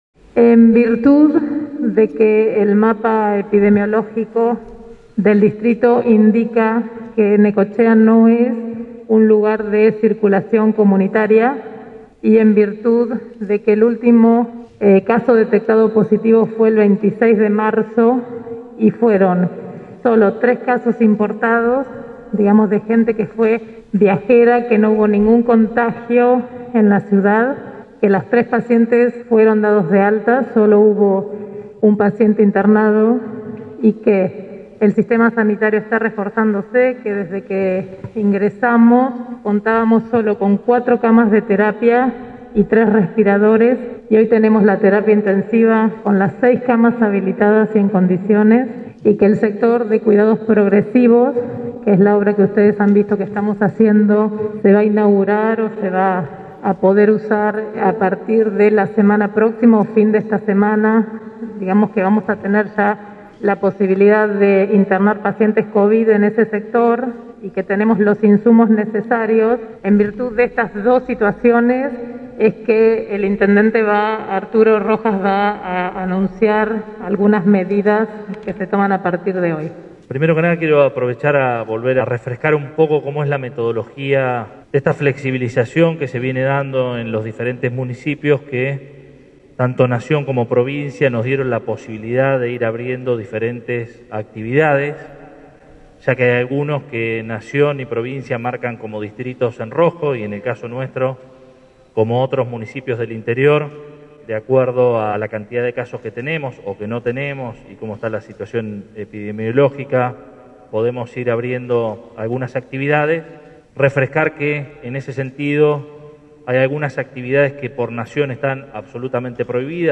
El Intendente dio a conocer en conferencia de prensa los comercios habilitados en este marco de pandemia Coronavirus, luego de reunirse con el gobernador Axel Kicillof.